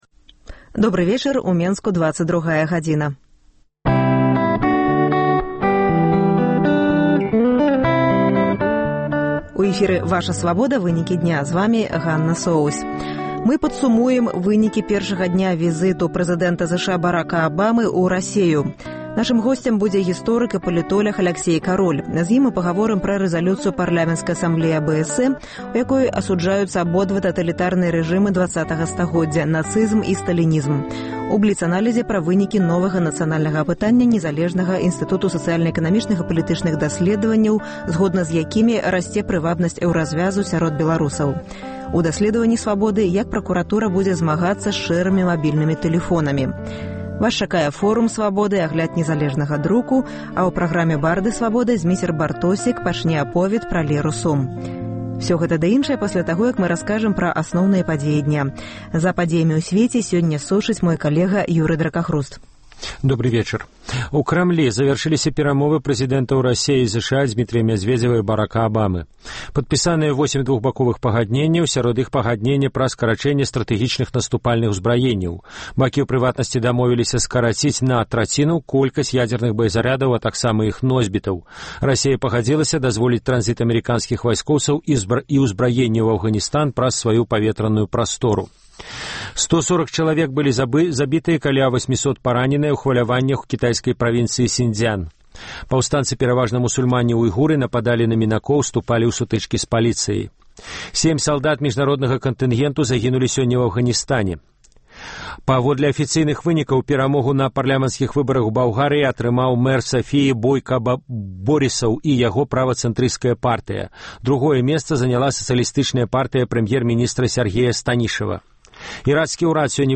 Асноўныя падзеі, бліц-аналіз, досьледы і конкурсы, жывыя гутаркі, камэнтары слухачоў, прагноз надвор'я, "Барды Свабоды".